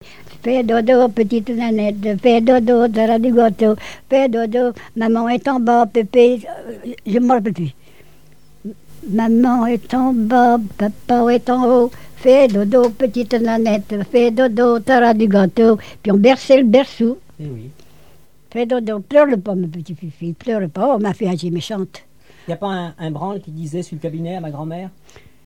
Mémoires et Patrimoines vivants - RaddO est une base de données d'archives iconographiques et sonores.
berceuse
collecte du répertoire de chansons, d'airs de branles et un conte
Pièce musicale inédite